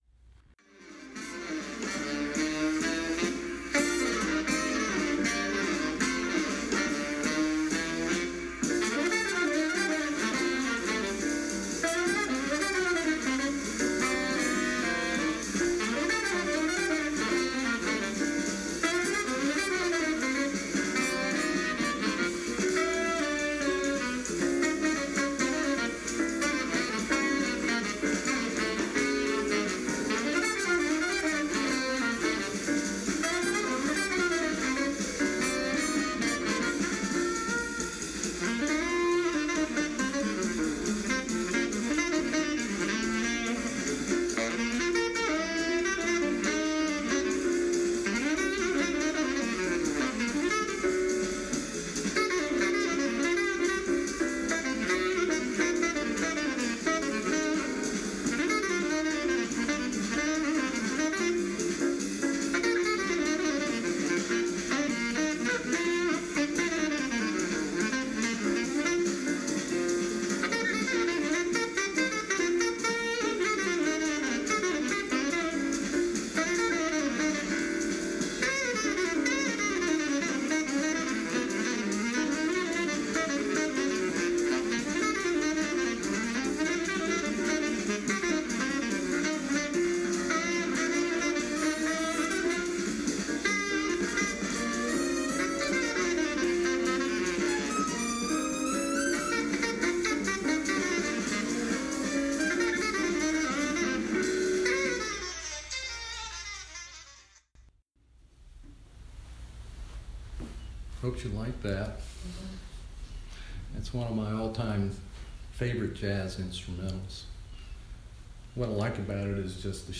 Dharma Talk
October 27 2018 Southern Palm Zen Group”